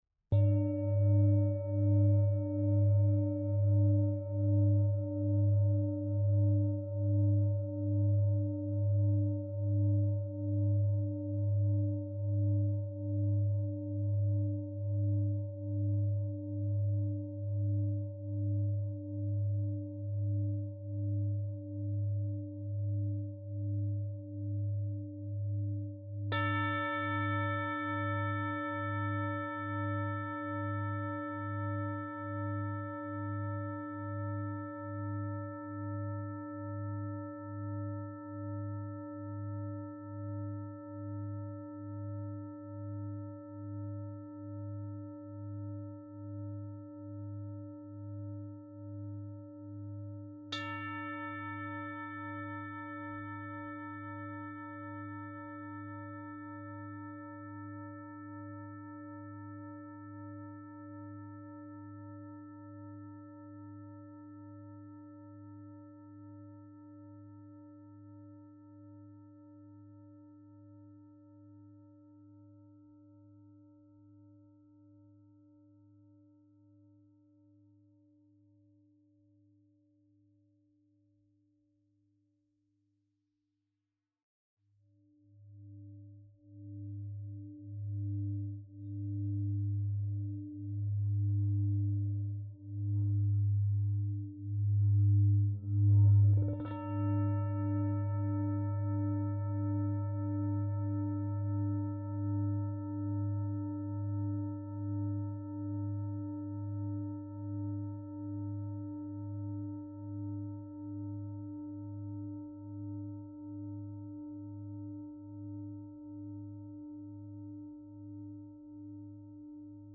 Tibetská misa ø 29 cm/tón G
Spievajúce Tibetské misy dovezené z Indie viac
Hraním na Tibetskú misu sa jej stena rozvibruje a vydáva nádherné harmonizujúci tóny.
Táto misa je dovezené z Indie a jej základný tón je D
Nahrávka tejto Tibetské misy na počúvanie tu